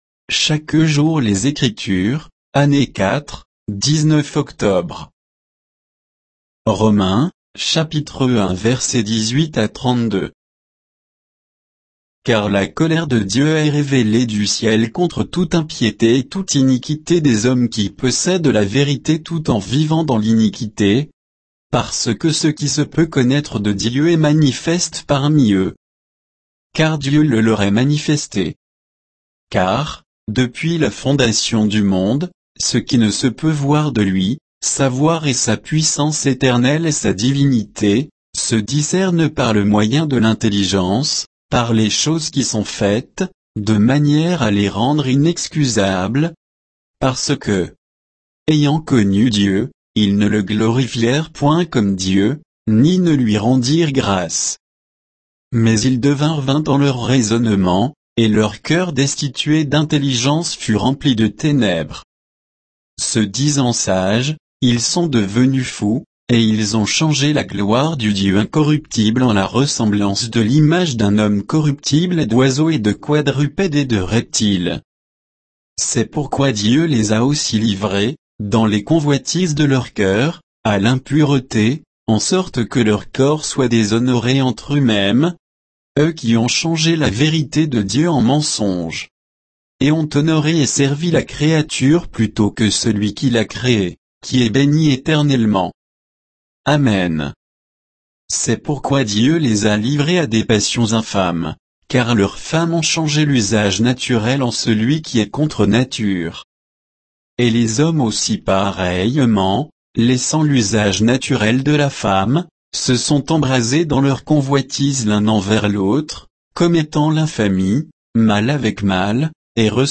Méditation quoditienne de Chaque jour les Écritures sur Romains 1